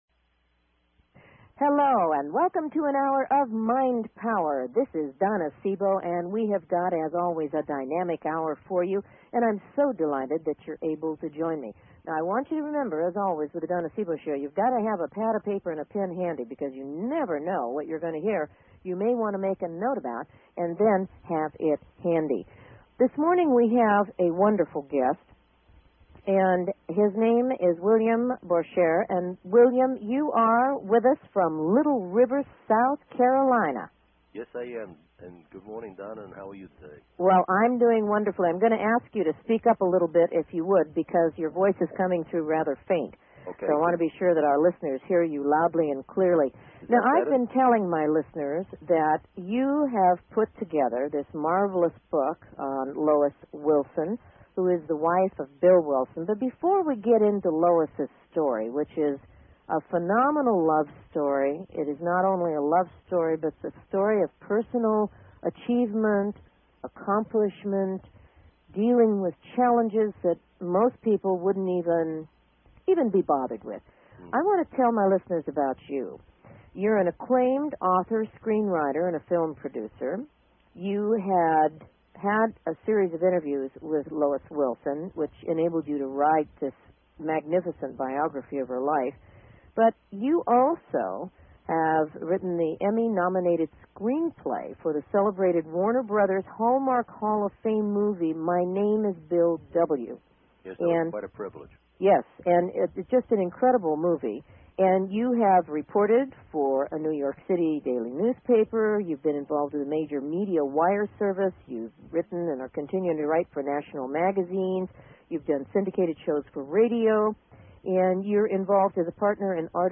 Talk Show Episode, Audio Podcast
Callers are welcome to call in for a live on air psychic reading during the second half hour of each show.